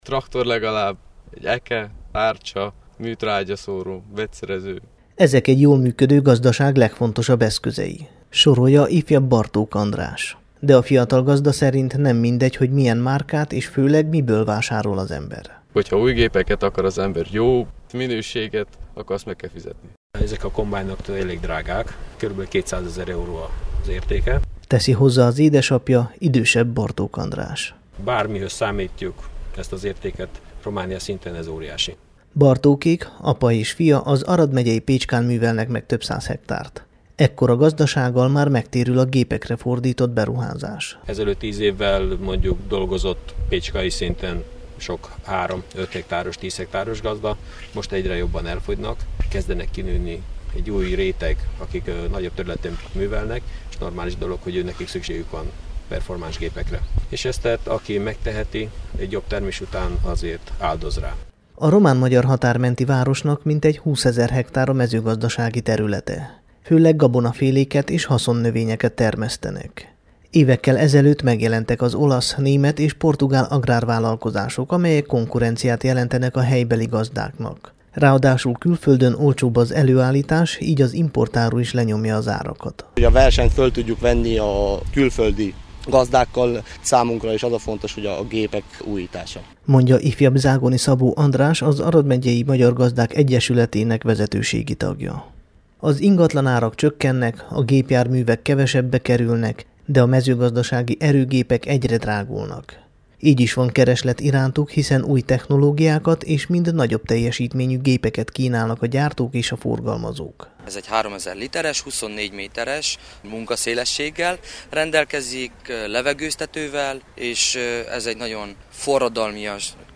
összeállítása a Temesvári Rádió számára készült.